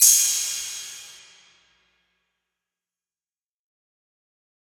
TM-88 Crash #02.wav